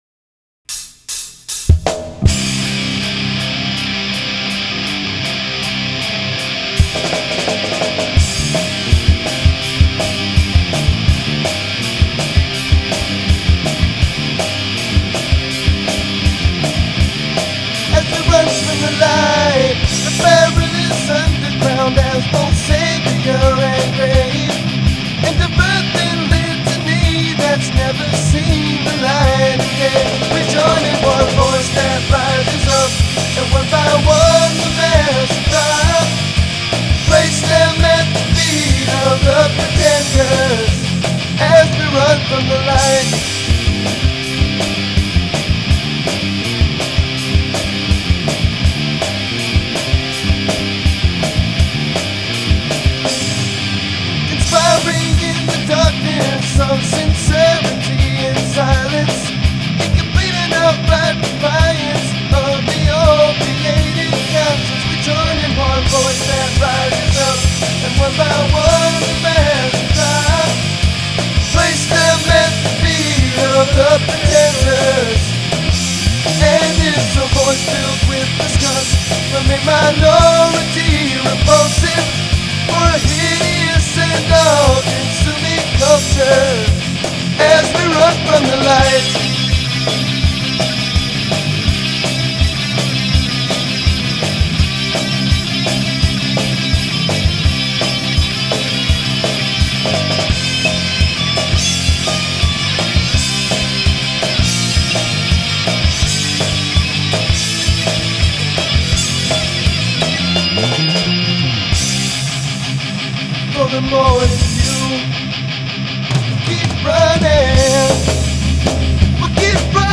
Vocals
Guitar
Drums
Bass
Hardcore , Punk